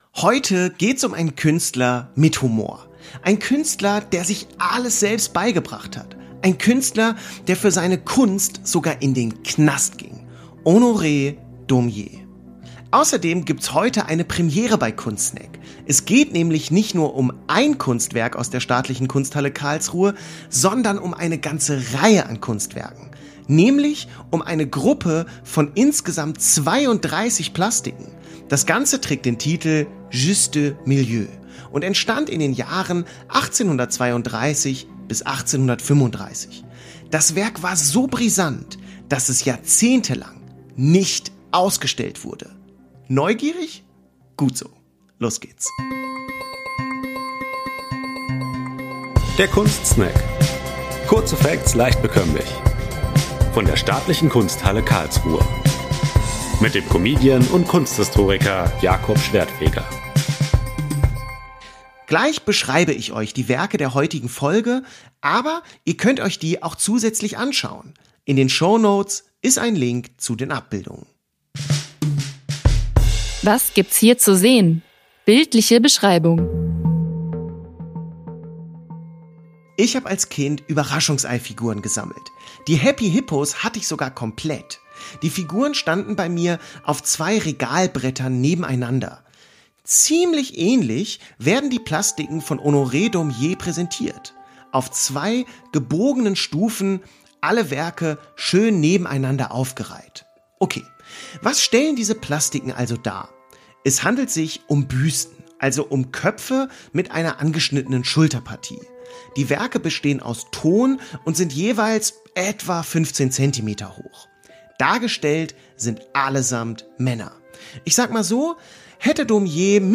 Ein Podcast der Staatlichen Kunsthalle Karlsruhe